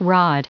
Prononciation du mot : rod